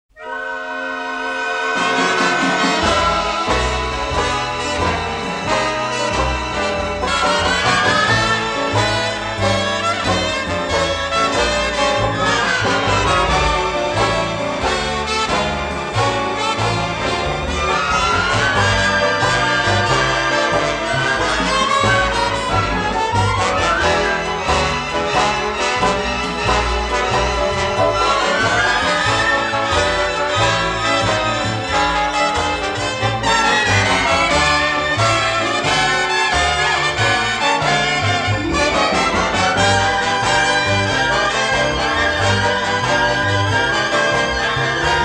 a deeply melodic, romantic and sophisticated score